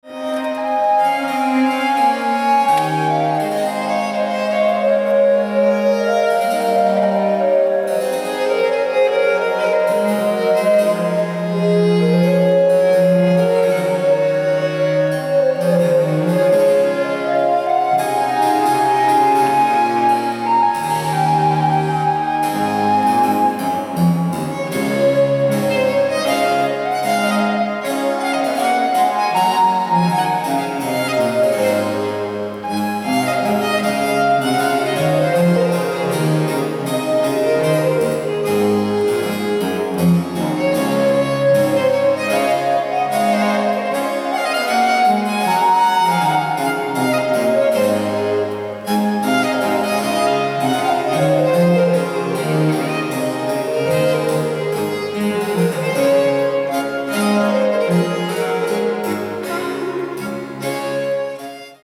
live opname in de Augustijnen kerk te Dordrecht 18 mei 2018. Les Carácteres de la Danse J- Féry Rebel. Prelude- courante. Barokensemble ConcertoValiante